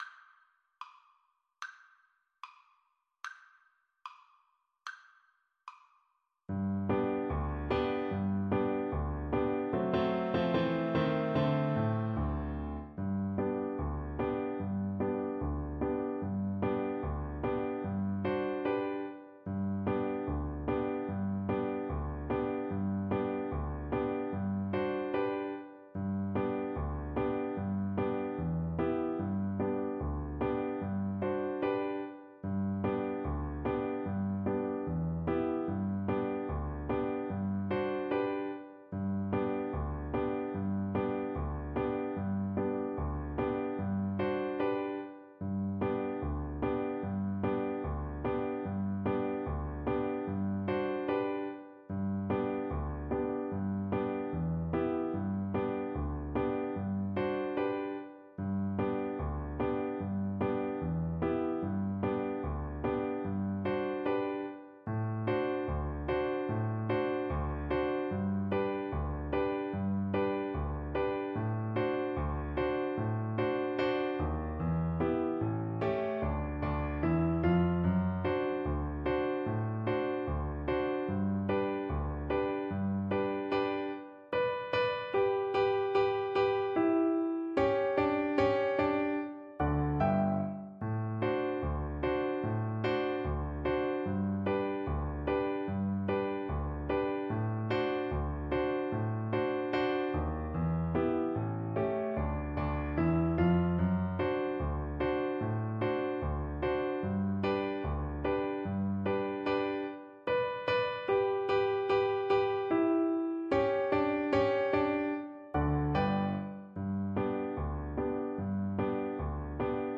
2/4 (View more 2/4 Music)
Not too fast = c.80
Cello  (View more Intermediate Cello Music)
Pop (View more Pop Cello Music)
Reels